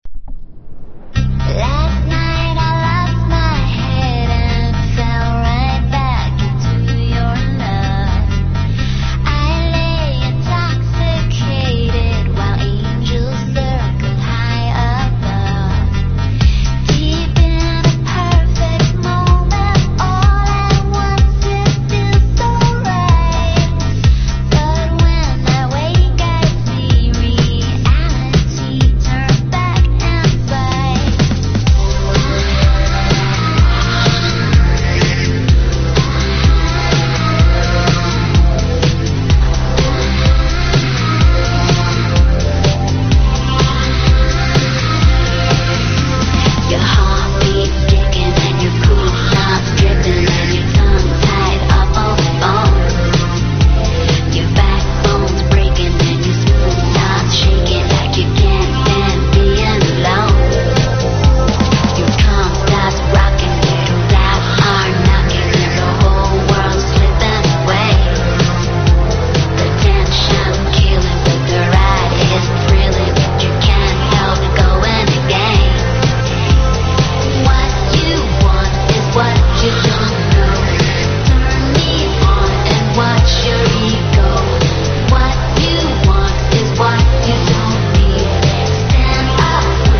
エレクトロなアレンジが効いたポップス大名曲!!
# INDIE DANCE# 00’s ROCK# ELECTRO